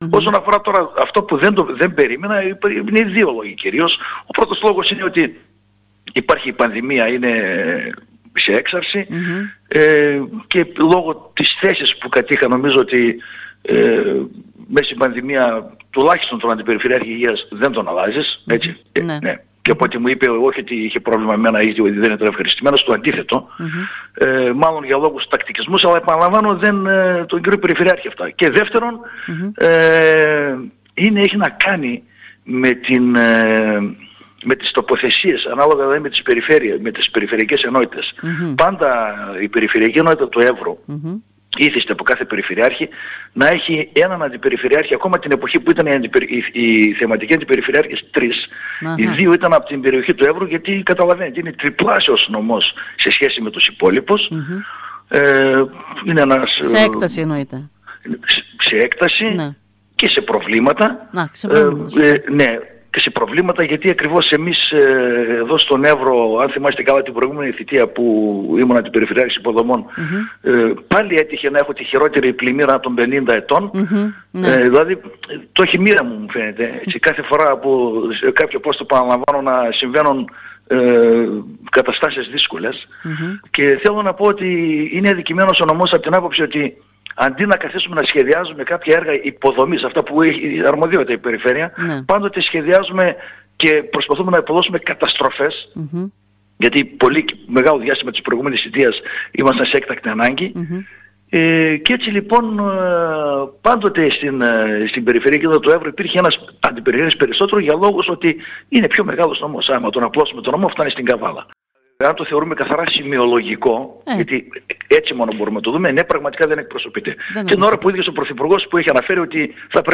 Μιλώντας σήμερα στην ΕΡΤ Ορεστιάδας ο κ. Βενετίδης διευκρίνισε ότι δεν περίμενε να αποχωρήσει για δυο λόγους.